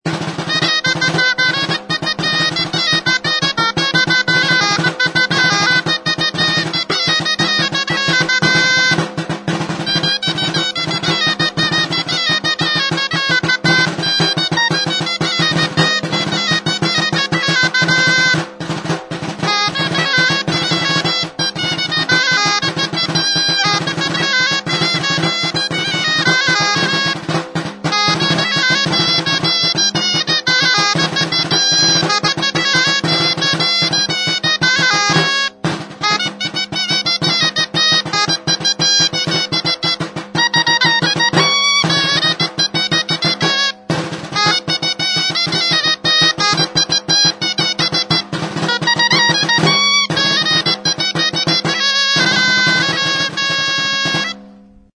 Membranophones -> Beaten -> Stick-beaten drums
HM Udako kontzertua.
Danbor honek metalezko kaxa zilindrikoa du. Larruzko 2 partxe ditu, eta metalezko tentsoreak. Atzekaldeko partxean bordoiak ditu, tebankatzeko metalezko tentsorearekin. Mintzaren kontra jotzeko zurezko bi makil ditu.